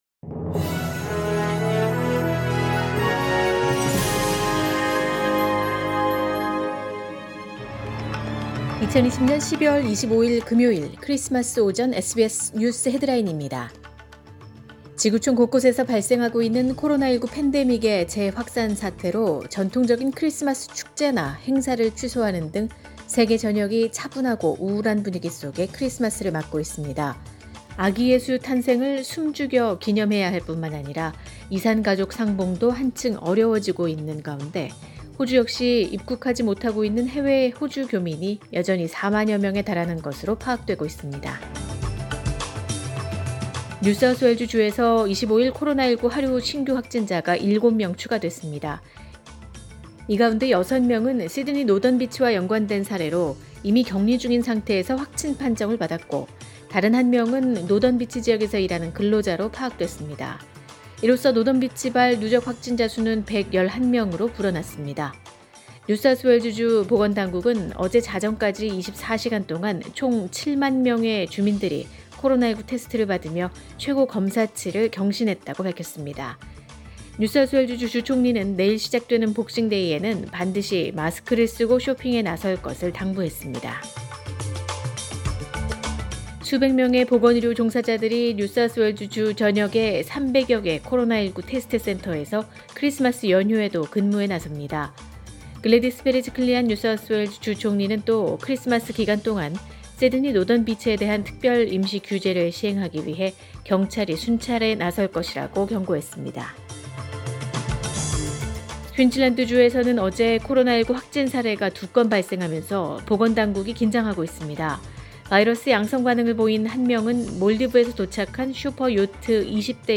2020년 12월 25일 금요일 오전의 SBS 뉴스 헤드라인입니다.